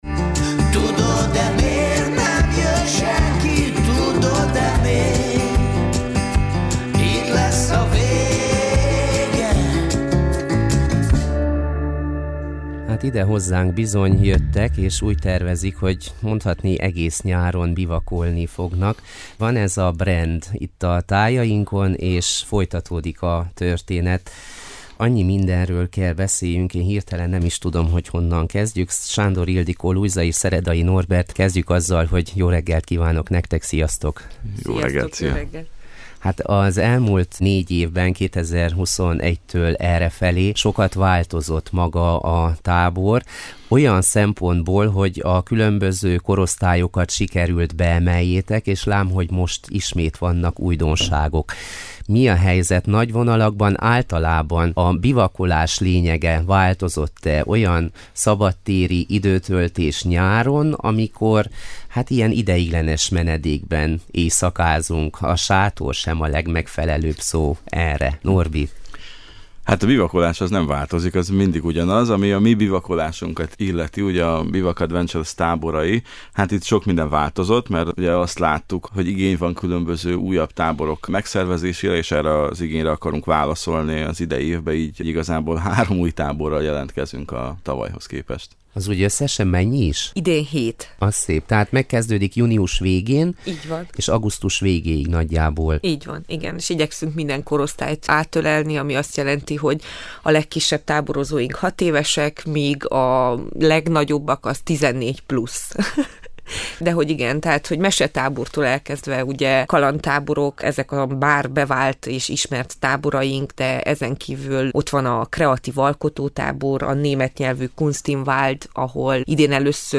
Beszélgetésünk meghallgatható a lejátszóra kattintva.